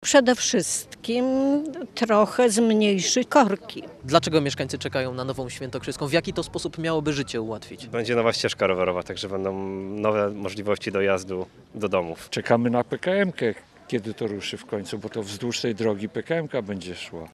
Opublikowany przez Aleksandrę Dulkiewicz Sobota, 4 października 2025 Co o rozpoczętej budowie sądzą mieszkańcy? Sprawdził to nasz reporter.